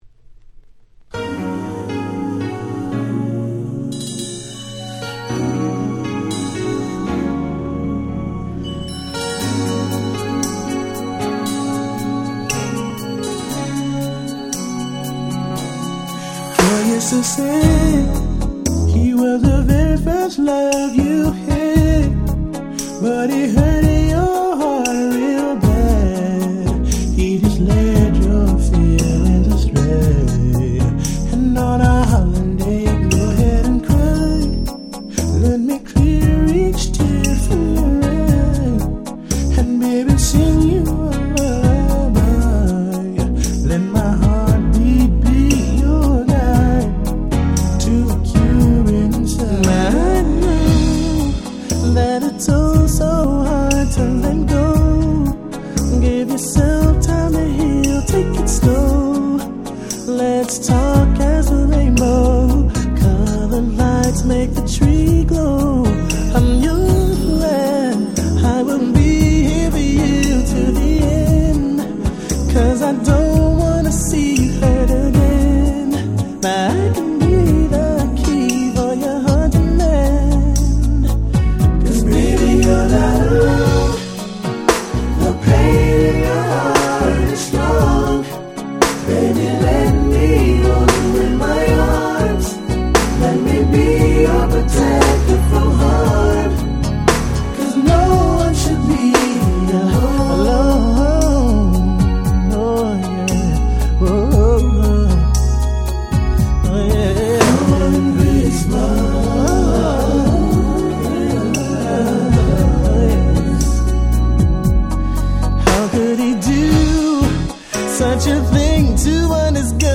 90's Slow Jam Classic !!